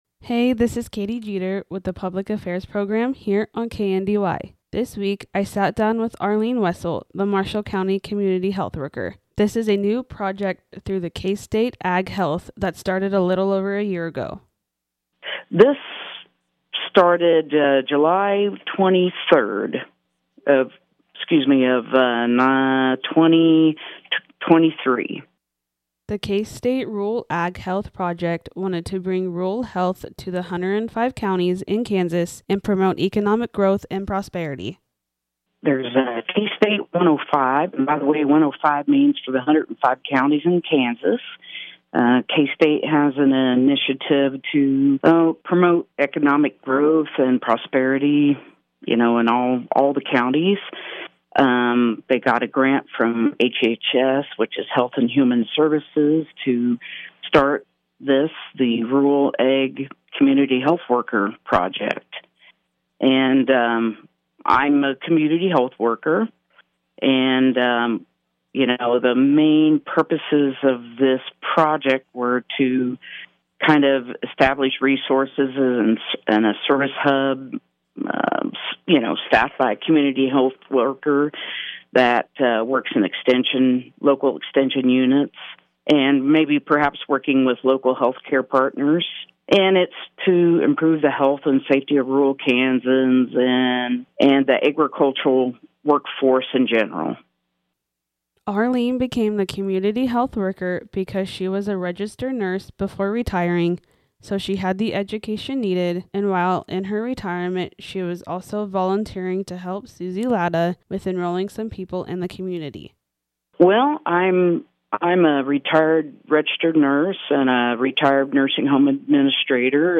KNDY NEWS PODCAST
interview